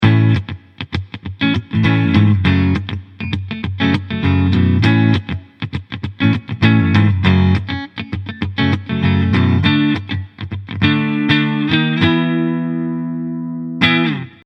We’re going to start off with a more or less ideal DI recording of a clean electric guitar, and then use Strip’s EQ, compressor, and expander to give it the tonal quality it deserves.
We’re aiming for a modern, and clean sound.
As you will notice yourself, the combination of compression and expansion really tightened things and brought out the dead notes nicely, which are crucial for the groove.
Clean-Guitar-Strip-V3-WET.mp3